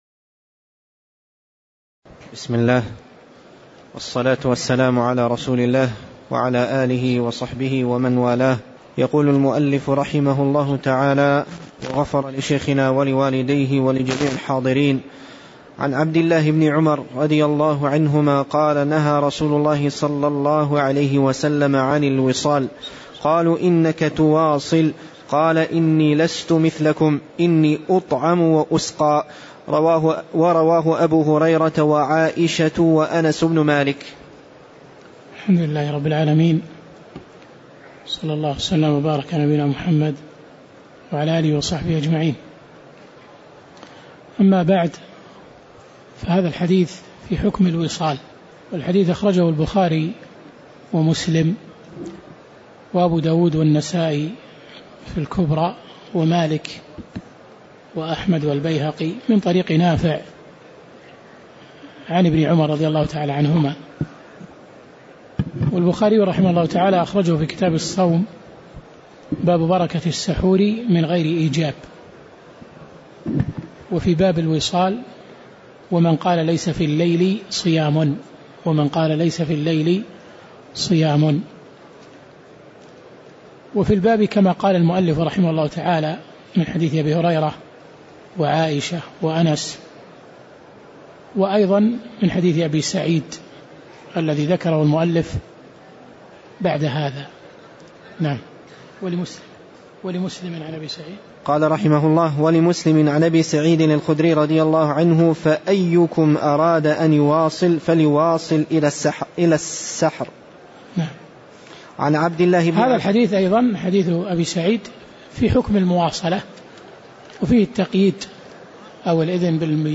تاريخ النشر ٢١ رجب ١٤٣٧ هـ المكان: المسجد النبوي الشيخ